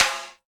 Index of /90_sSampleCDs/Roland - Rhythm Section/KIT_Drum Kits 7/KIT_Loose Kit